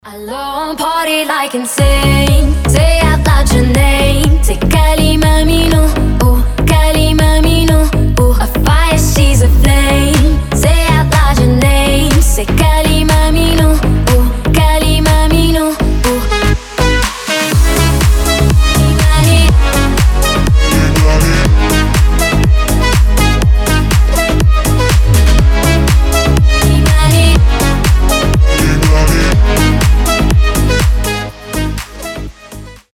• Качество: 320, Stereo
женский голос
house